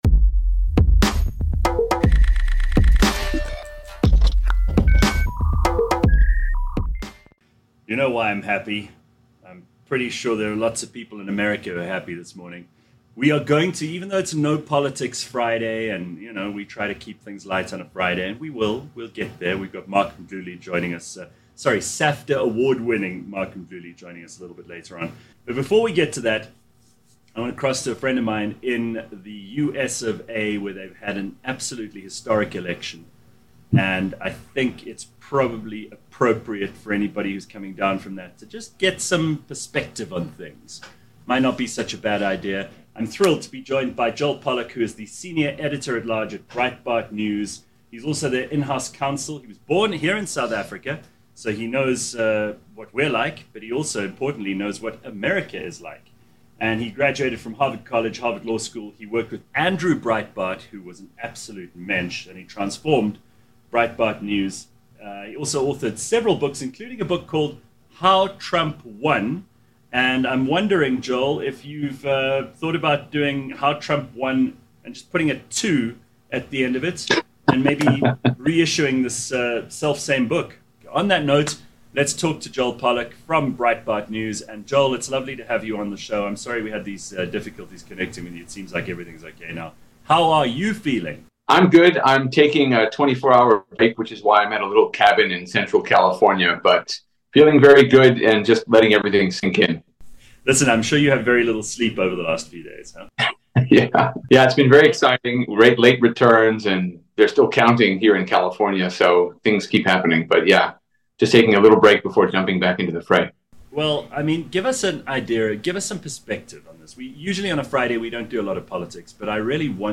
A live podcast show, that’s like a morning radio show, just much better.
Clever, funny, outrageous and sometimes very silly.